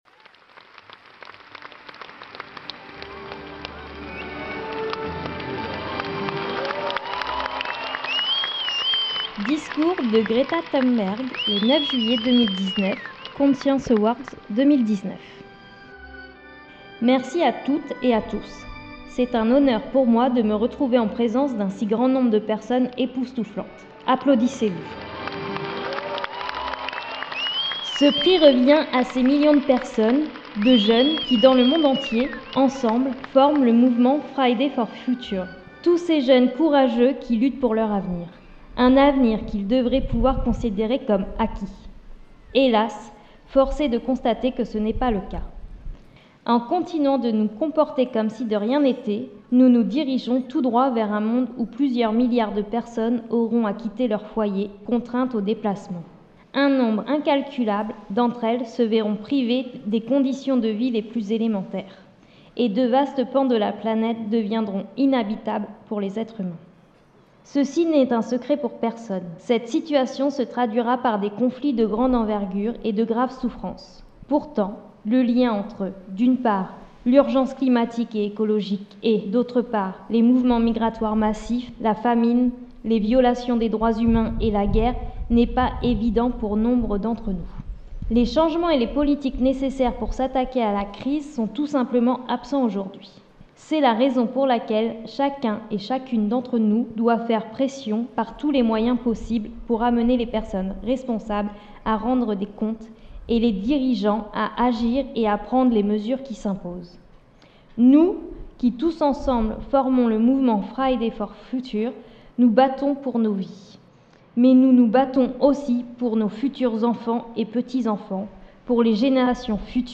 Mars 2022 – Discours de Greta Thunberg
Rubrique-Lecture-Mars-2022-Greta-Thunberg-.mp3